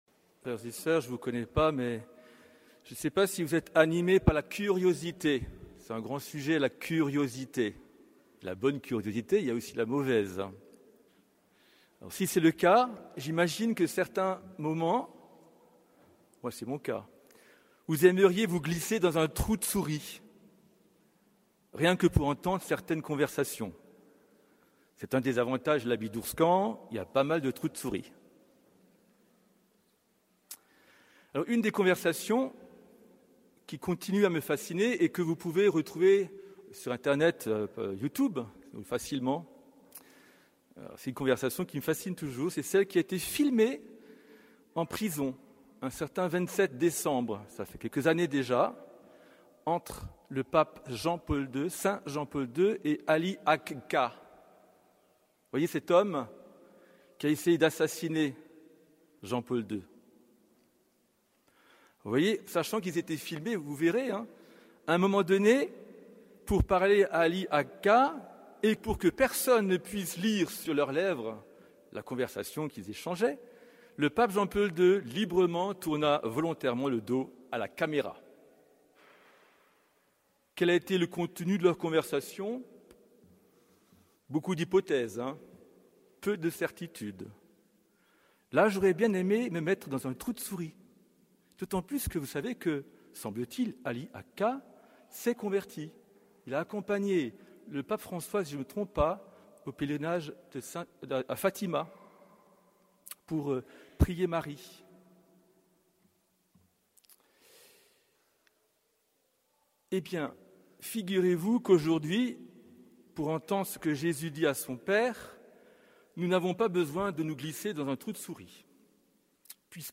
Homélie du septième dimanche de Pâques